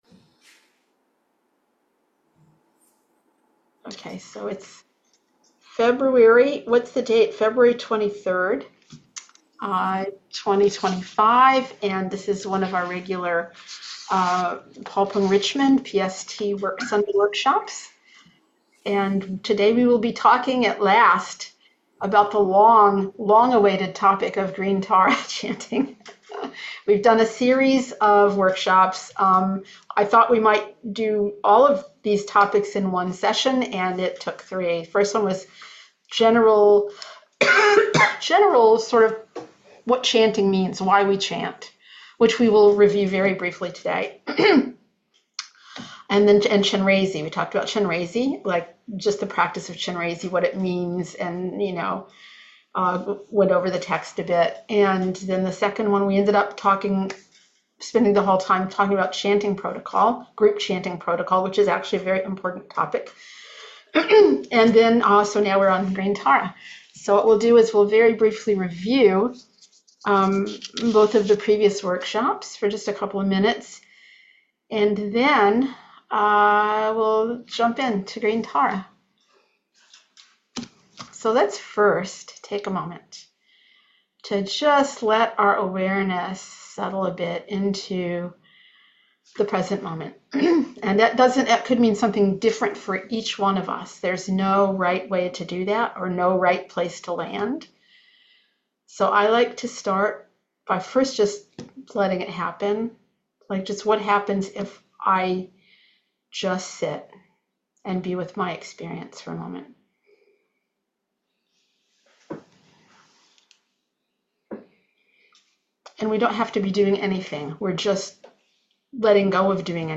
In this installment of our workshops we’ll explore how chanting practices support our path of awakening as an adjunct to shamatha meditation and the process of connecting with the true nature of our mind and reality; how these practices are typically structured; and the mechanics of leading and participating in group chanting as well as practicing on one’s own. We’ll chant the Green Tara practice together.